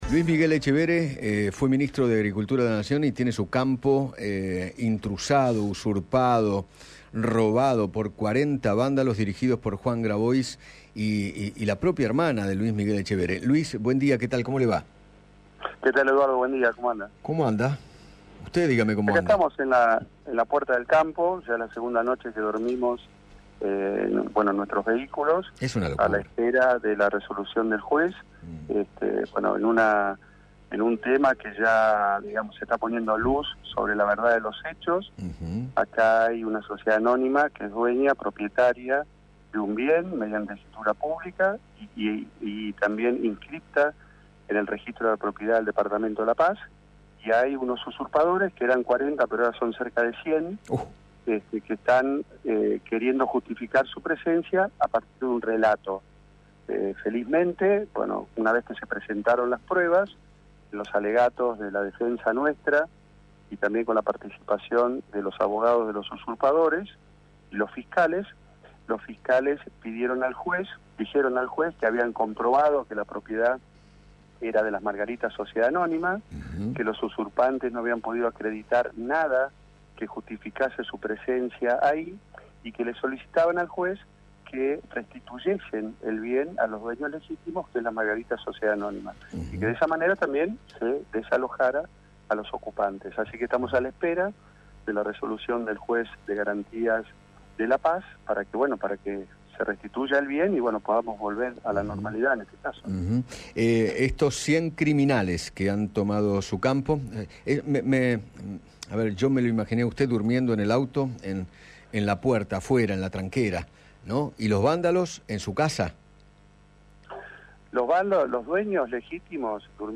Luis Miguel Etchevehere, ex Ministro de Agricultura, Ganadería y Pesca de la Nación, dialogó con Eduardo Feinmann sobre la usurpación de su campo familiar en la localidad de Santa Elena y dio detalles acerca de lo que está sucediendo.